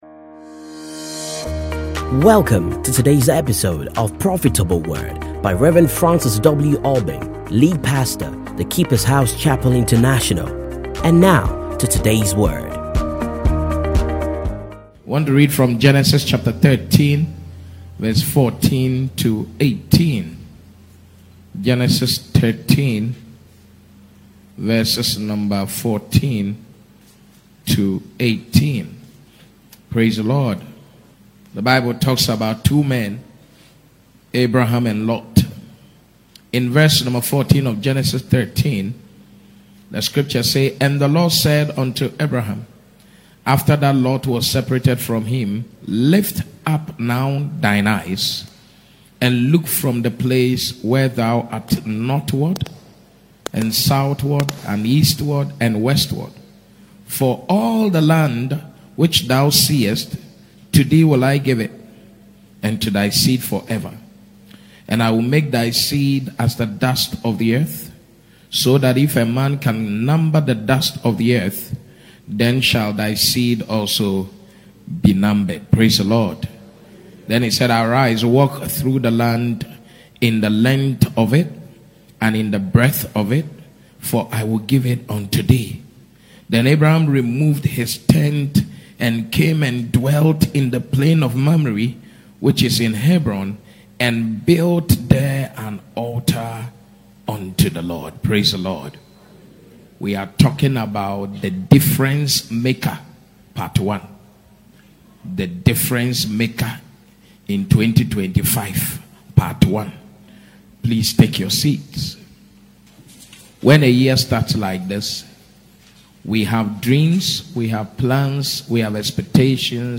Stay Connected And Enjoy These Classic Sermons